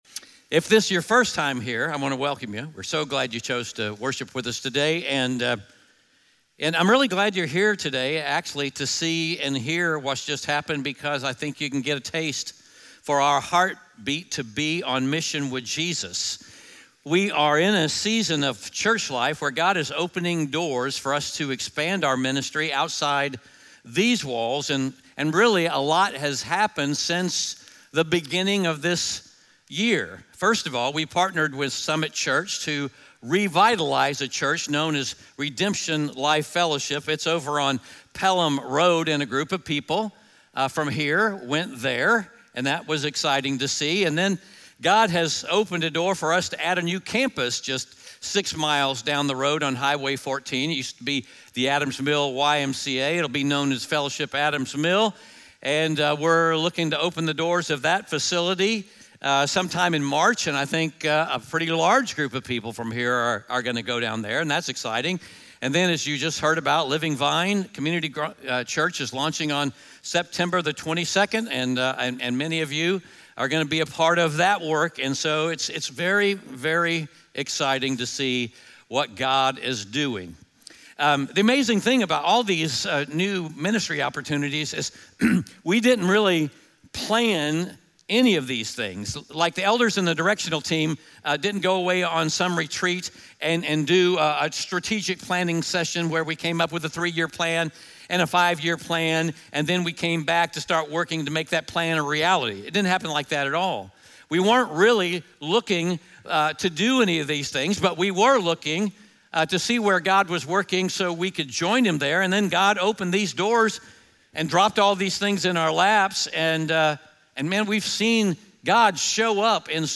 Galatians 1:6-12 Audio Sermon Notes (PDF) Ask a Question Scripture: Galatians 1:6-12 SERMON SUMMARY Why is being clear on the Gospel so vitally important?